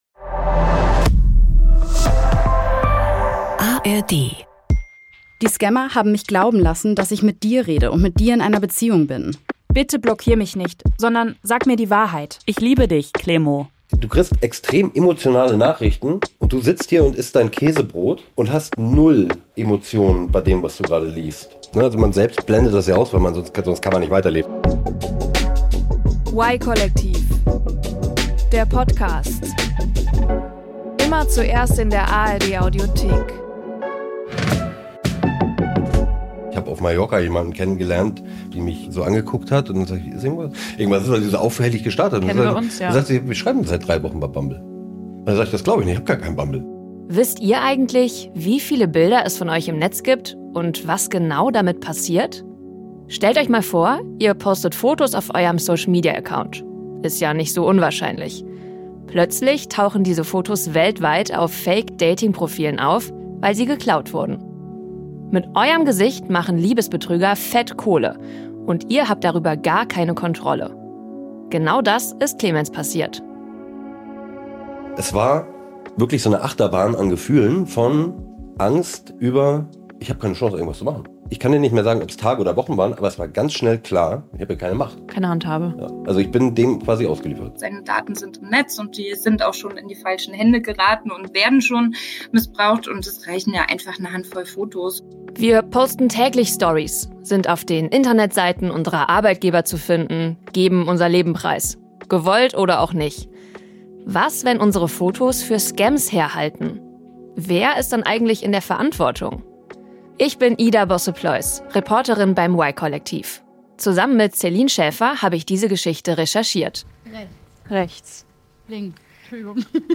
Bei uns gibt’s die großen gesellschaftlichen Themen unserer Zeit, erzählt in persönlichen Geschichten. Jeden 2. Freitag eine neue Reportage. Y-Kollektiv – der junge Doku-Podcast der ARD.